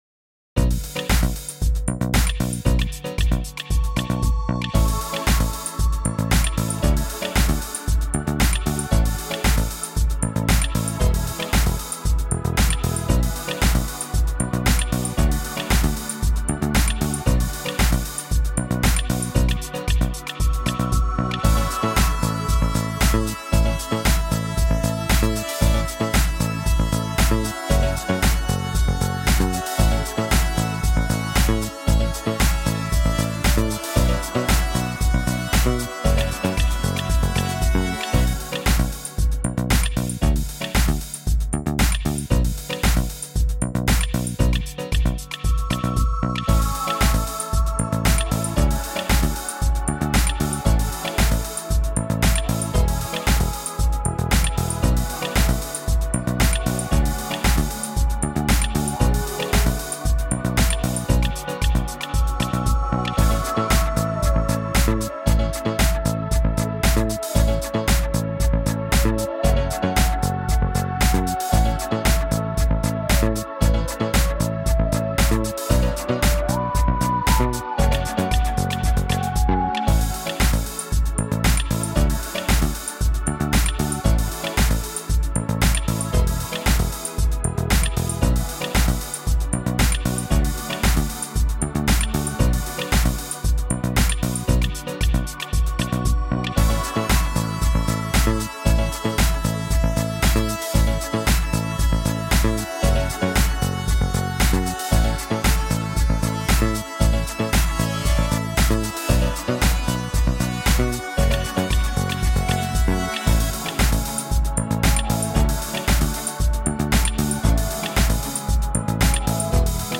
Музыка без слов